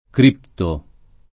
vai all'elenco alfabetico delle voci ingrandisci il carattere 100% rimpicciolisci il carattere stampa invia tramite posta elettronica codividi su Facebook crittare v. (inform.); critto [ kr & tto ] — anche criptare : cripto [ kr & pto ]